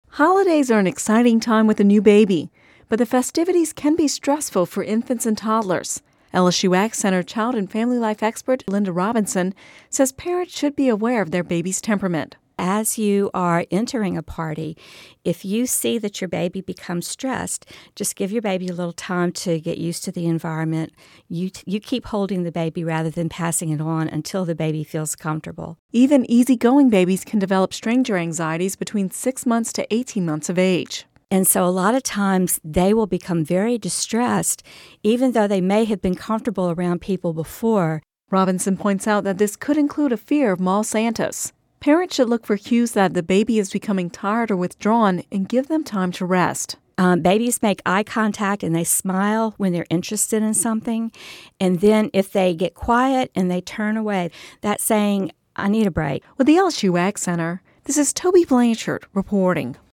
Description:(Radio News 12/13/10) Holidays are an exciting time with a new baby, but the festivities can be stressful for infants and toddlers.